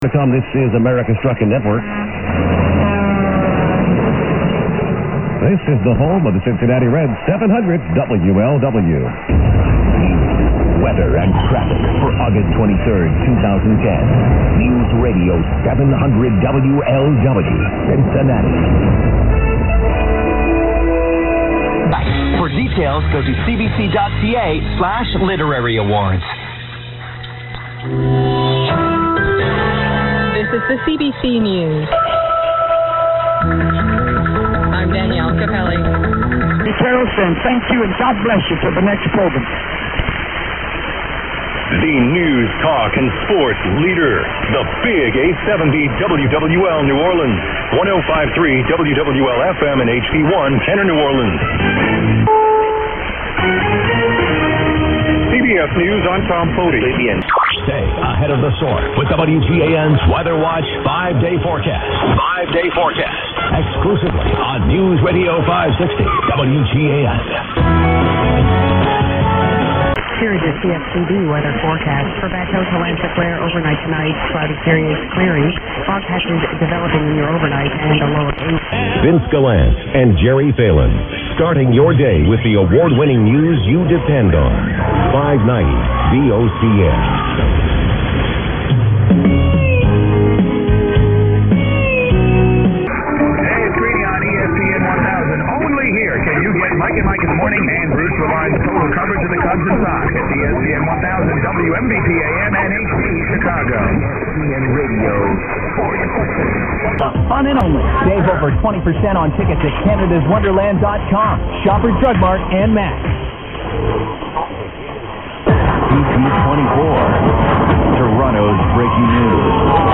The file length is 5m or so, and includes some much weaker and less common stns today.